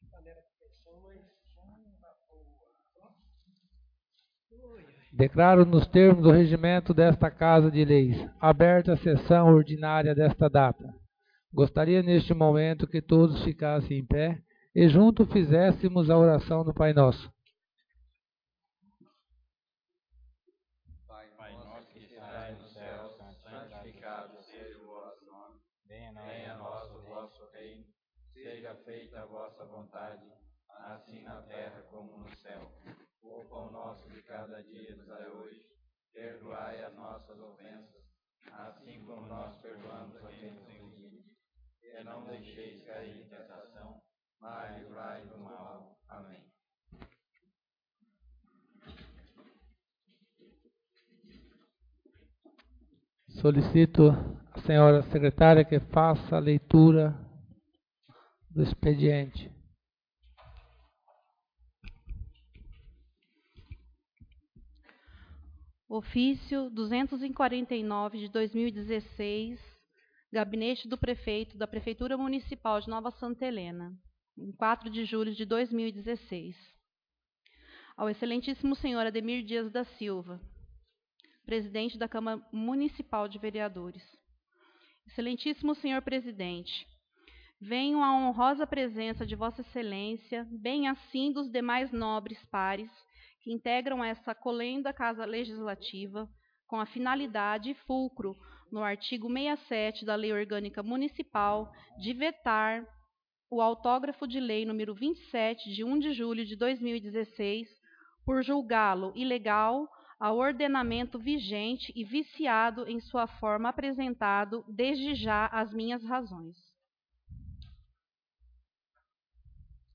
Sessão Ordinária